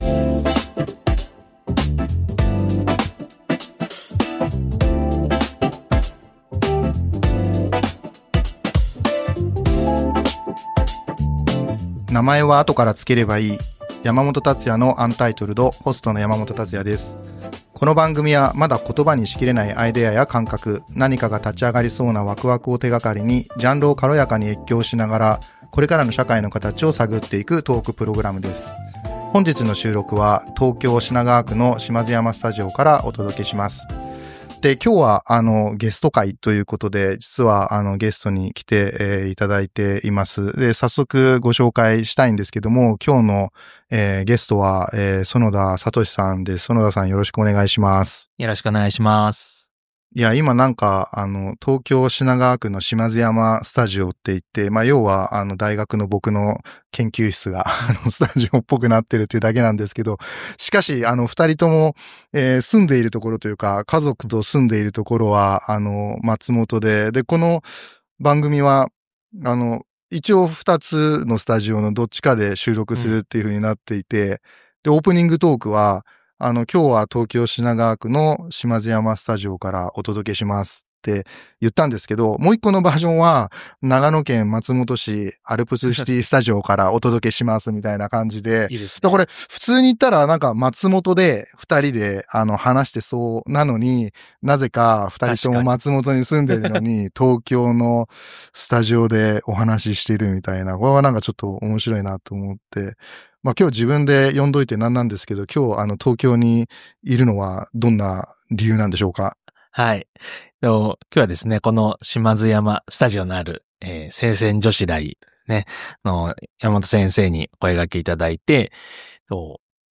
この番組は、まだ言葉にしきれないアイデアや感覚、何かが立ち上がりそうなワクワクを手がかりに、ジャンルを越境しつつ、これからの社会のかたちを探っていくトークプログラムです。